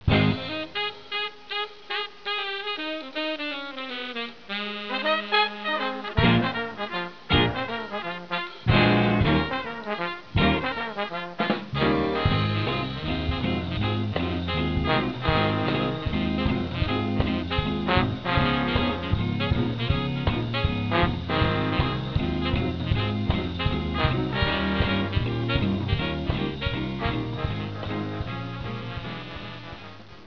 Trumpet Solo